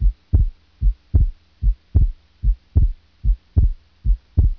Normal-Split-Second-Sound.mp3